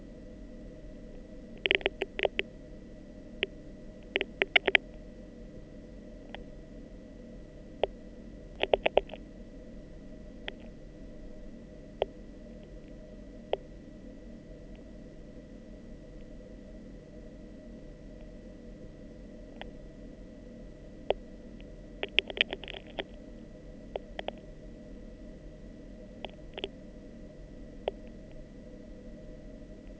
The present paper analyzes the sounds emitted by pre-hatching chicks, focusing on those named as “clicks,” which are thought to mediate pre-hatching social interactions and hatching synchronization.
As hatching approaches, clicks evolve from isolated events to highly organized hierarchical clusters.